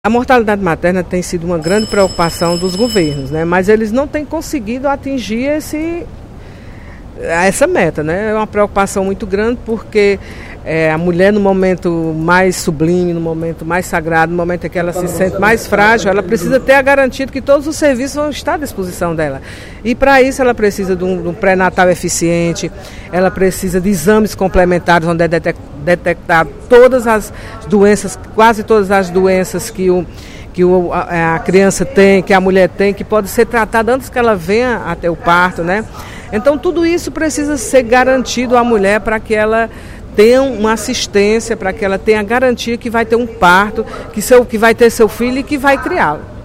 A deputada Mirian Sobreira (PSB) abriu os trabalhos da sessão plenária desta terça-feira (05/03), alertando para a situação da mortalidade materna no Ceará.